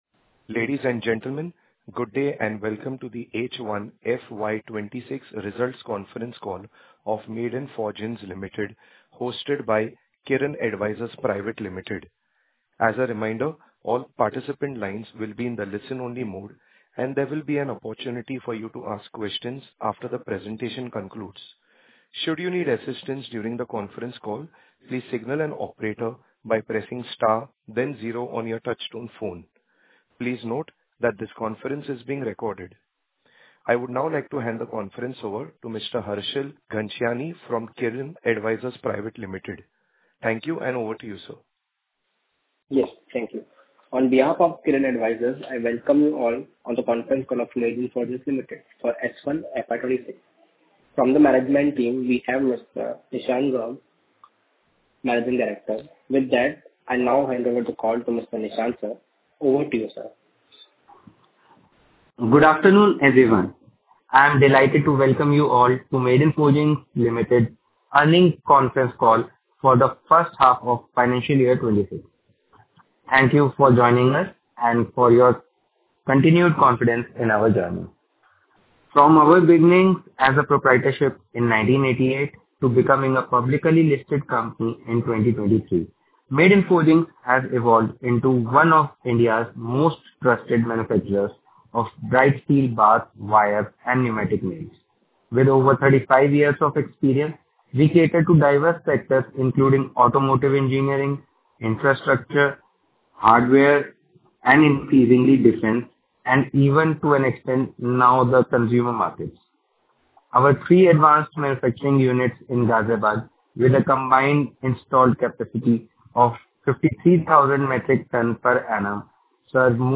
Audio Recording of Earning Conference Call for Financial Year 2022-2023